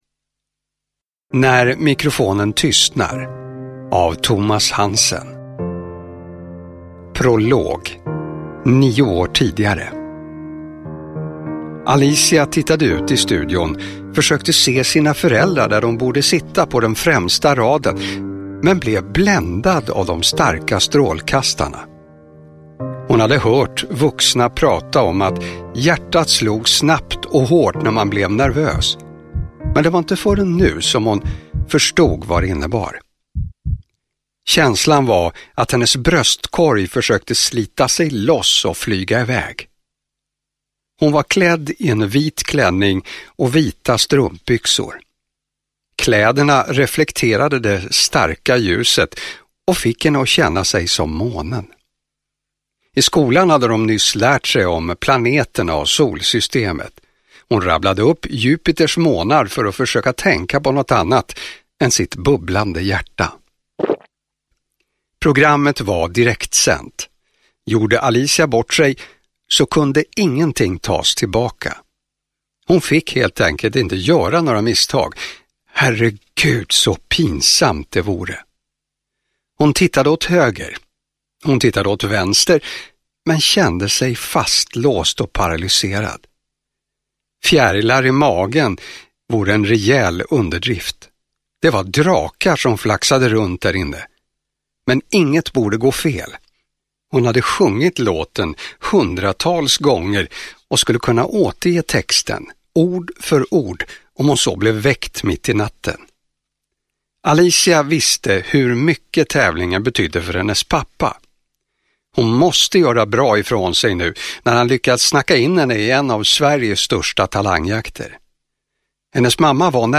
När mikrofonen tystnar (ljudbok) av Thomas Hansen